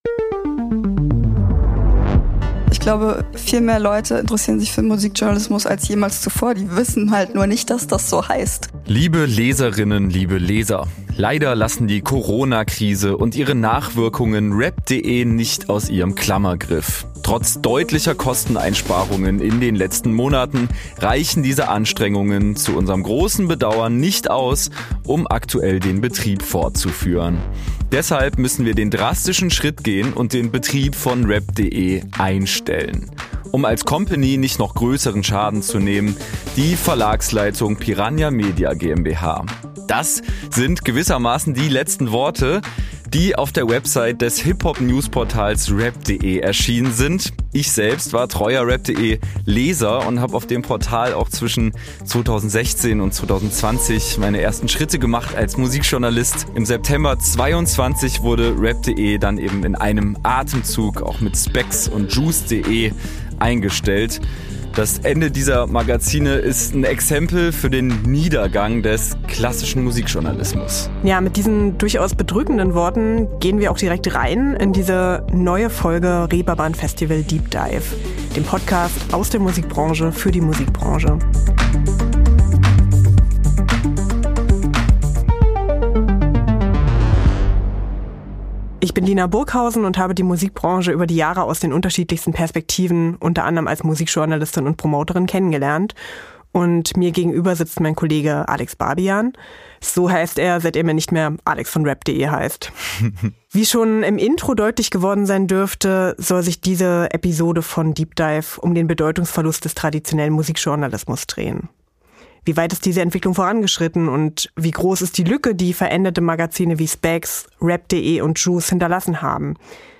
Studio: German Wahnsinn